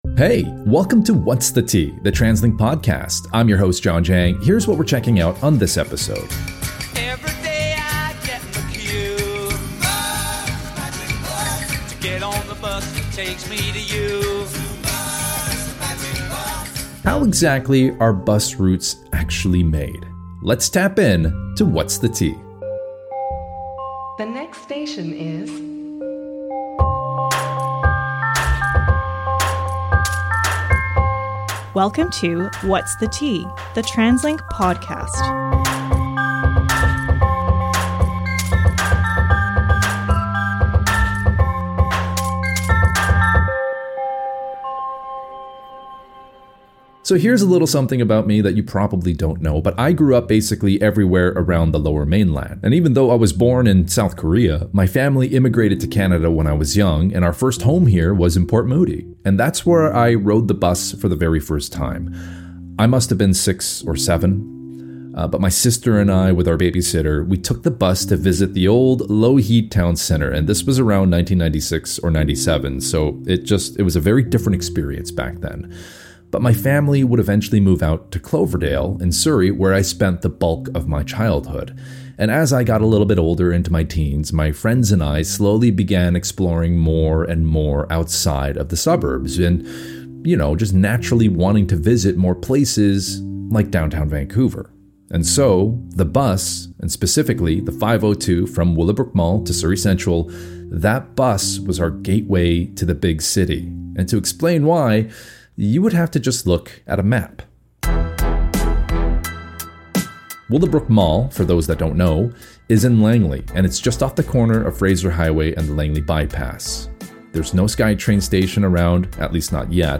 1 More interviews from the 2025 NAMA convention floor – Are industry newcomers driving record attendance? 23:24